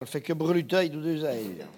Localisation Sallertaine ( Plus d'informations sur Wikipedia ) Vendée ( Plus d'informations sur Wikipedia ) France
Catégorie Locution